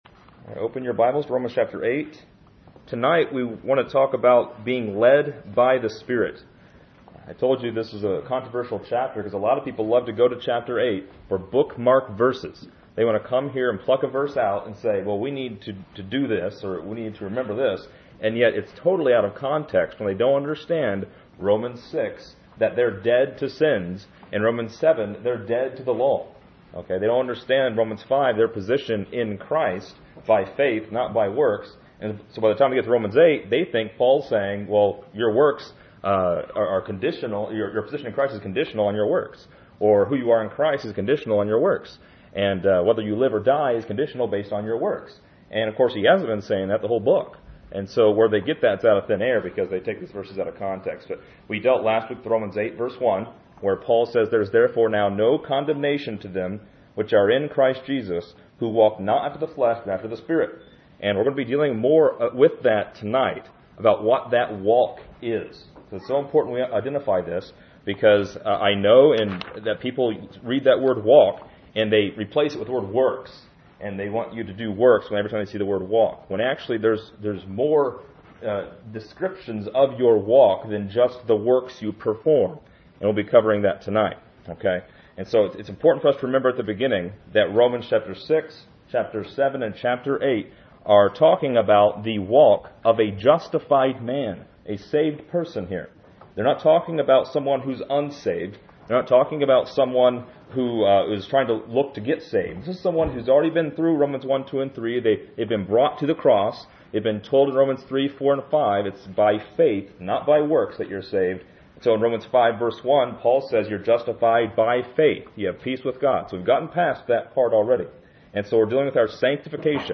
This lesson is part 29 in a verse by verse study through Romans titled: Led by the Spirit.